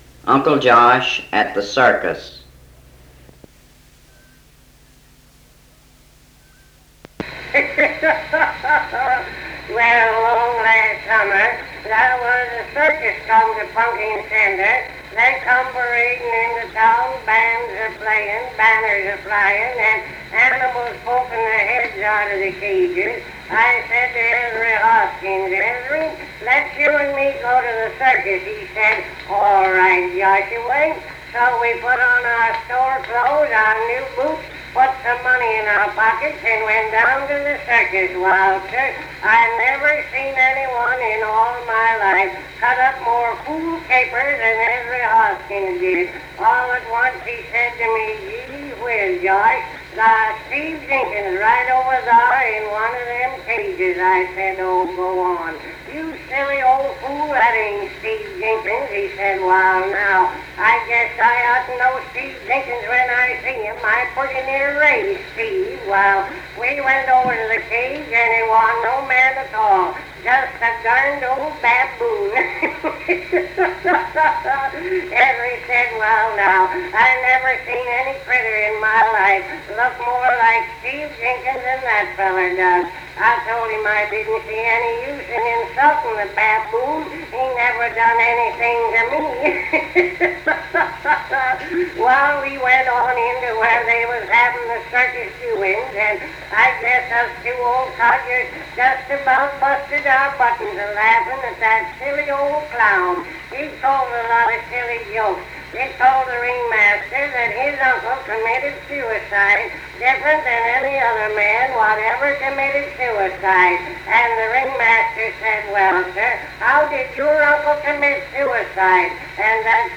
Cal Stewart's comedic routine, Uncle Josh at the circus.
American wit and humor